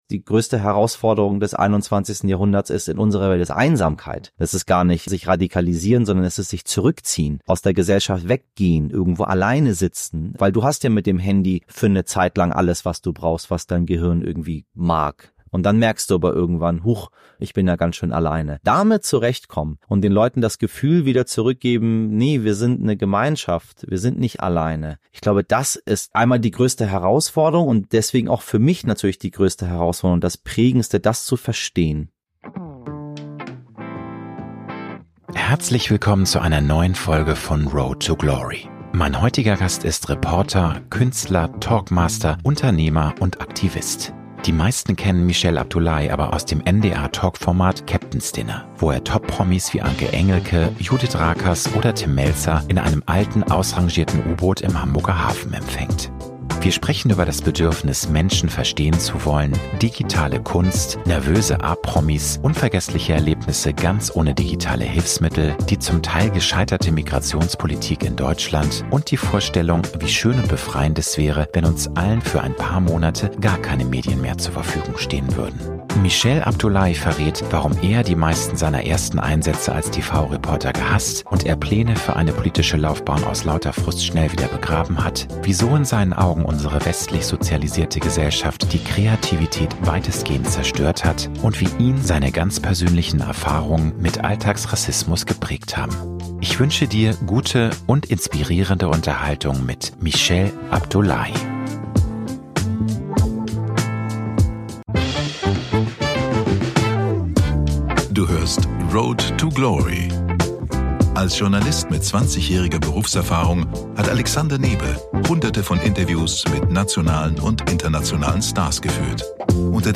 Mein heutiger Gast ist Reporter, Künstler, Talkmaster, Unternehmer und Aktivist.